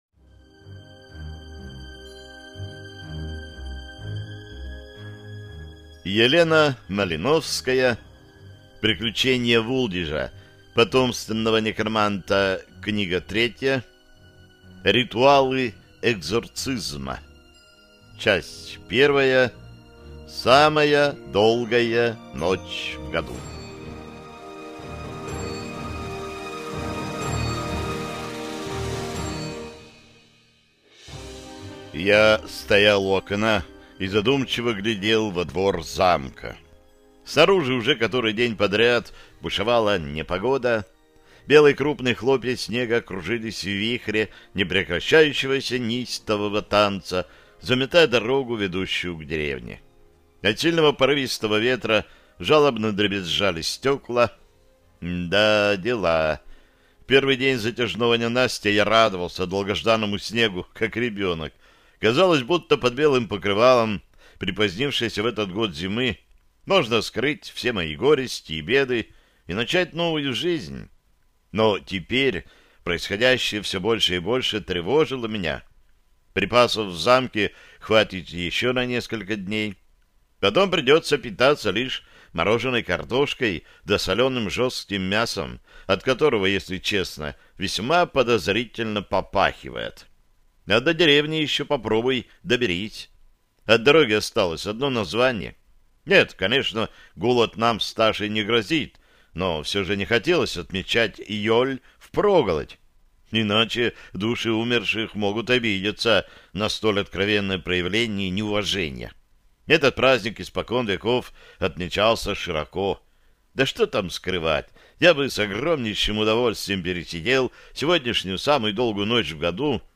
Аудиокнига Ритуалы экзорцизма | Библиотека аудиокниг